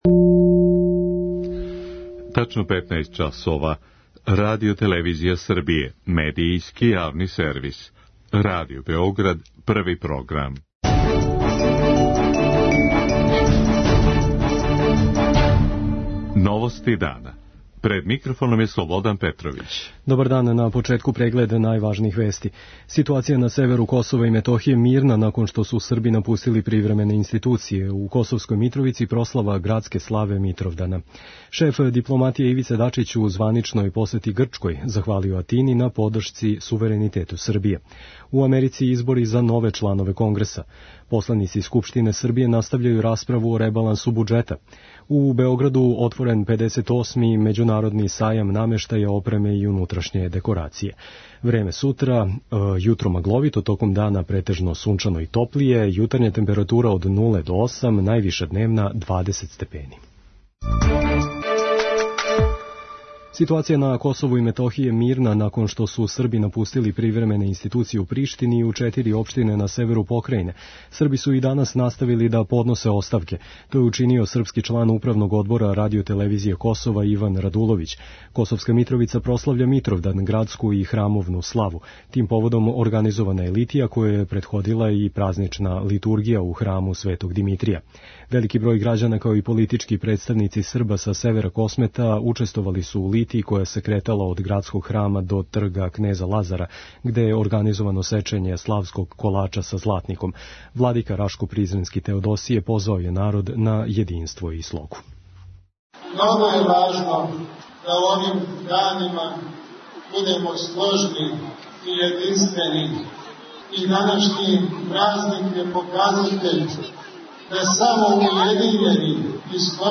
novosti0811.mp3